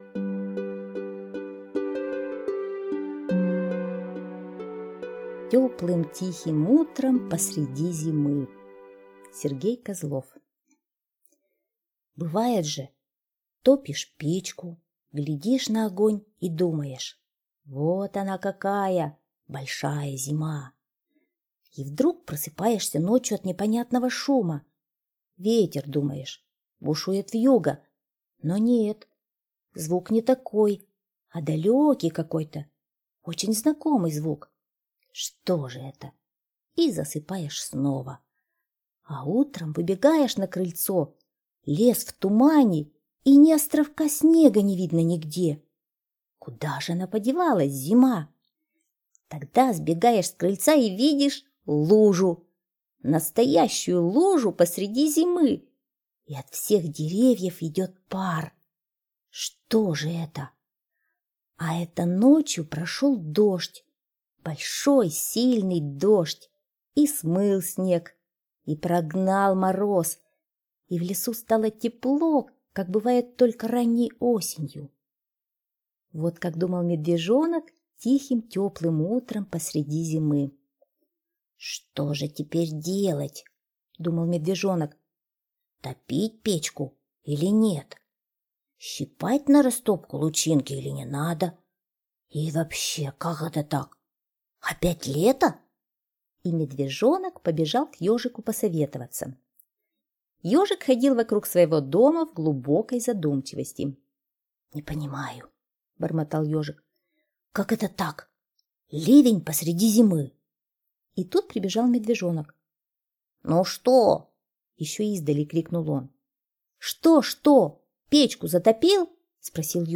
Слушайте Теплым тихим утром посреди зимы - аудиосказка Козлова С.Г. Сказка про неожиданную оттепель после ливня в самом начале зимы.